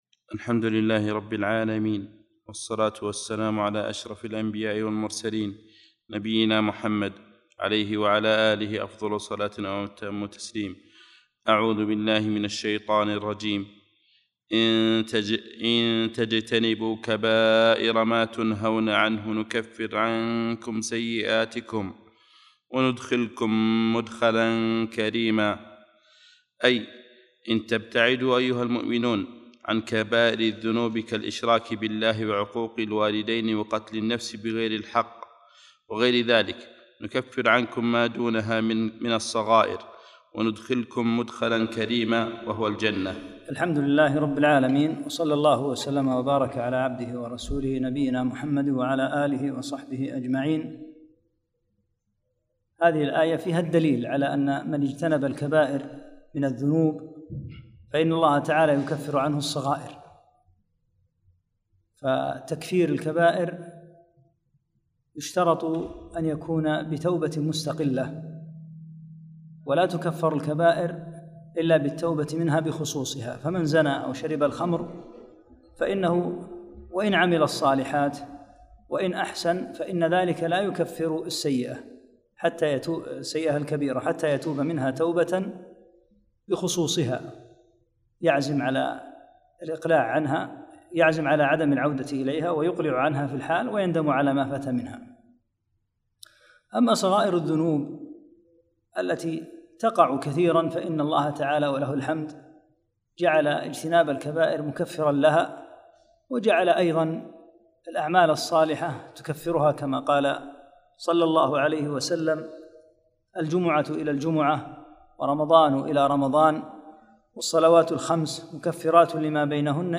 5- الدرس الخامس